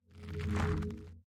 Minecraft Version Minecraft Version latest Latest Release | Latest Snapshot latest / assets / minecraft / sounds / block / chorus_flower / grow1.ogg Compare With Compare With Latest Release | Latest Snapshot